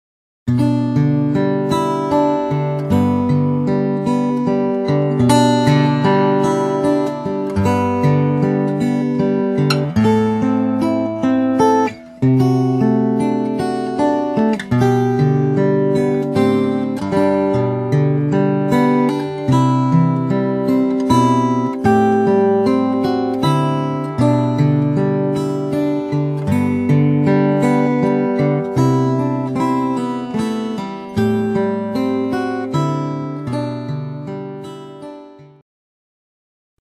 Fingerstyle guitar tab arrangements